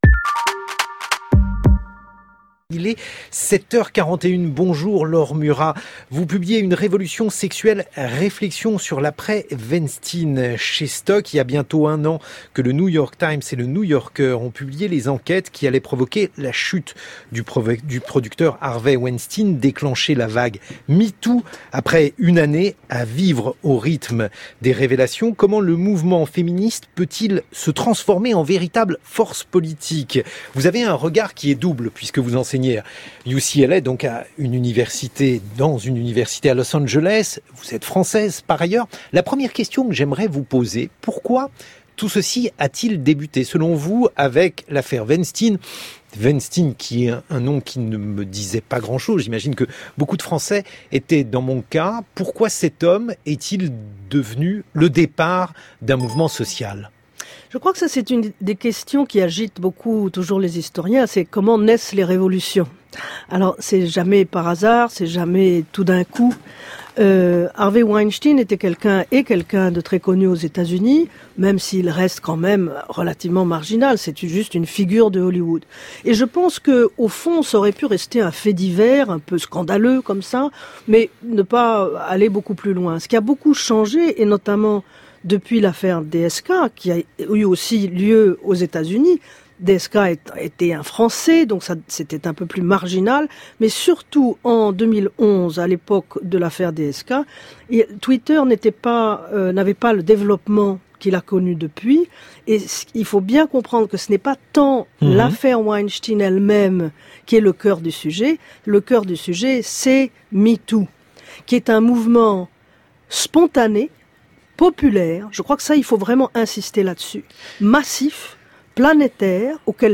Diffusé sur France Culture le 2 octobre 2018